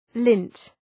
Προφορά
{lınt}